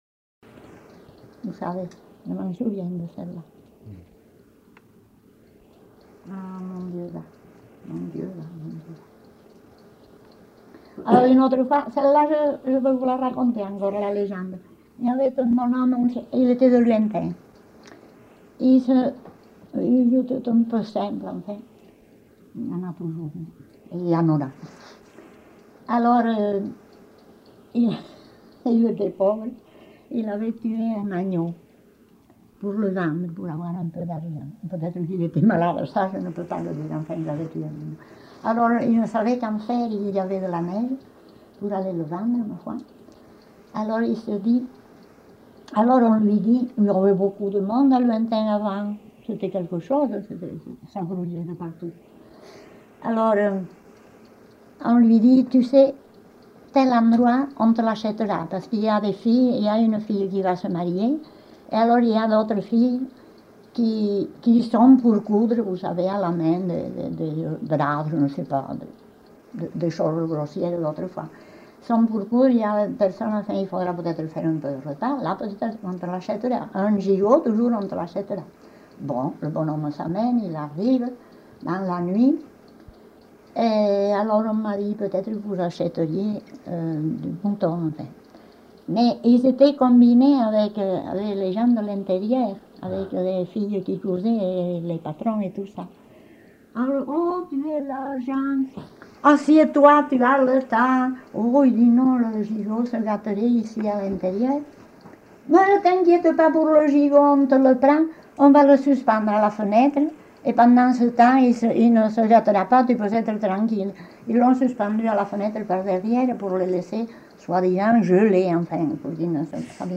Genre : conte-légende-récit
Type de voix : voix de femme Production du son : parlé
Classification : récit anecdotique